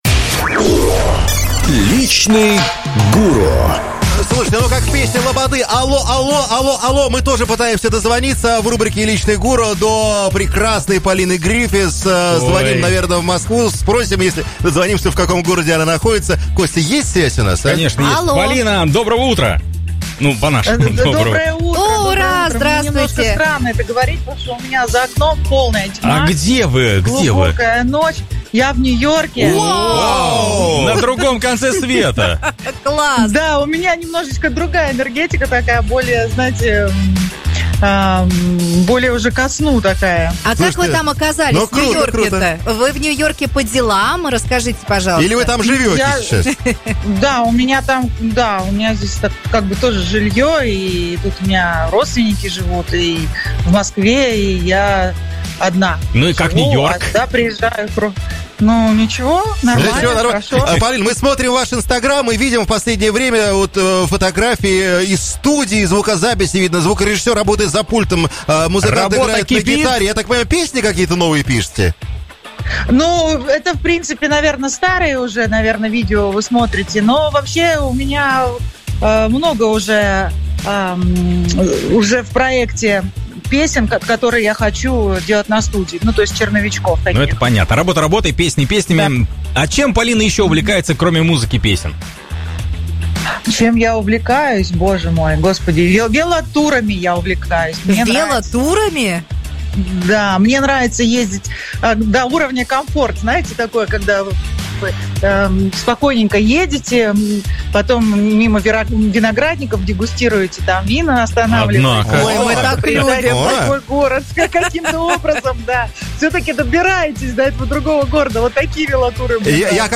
У нас на связи Полина Гриффис – певица и экс-солистка группы «А-Студио».